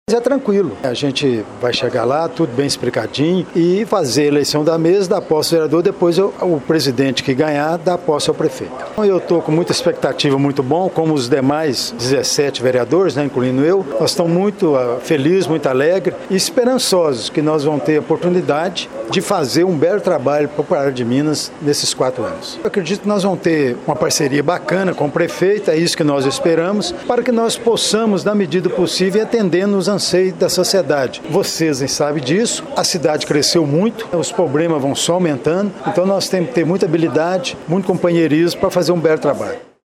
Em conversa com a nossa reportagem Délio falou sobre esta primeira missão em seu retorno à Câmara Municipal.
Animado, o vereador eleito falou sobre a expectativa para o seu quinto mandato e o desejo de uma boa relação com os colegas do legislativo.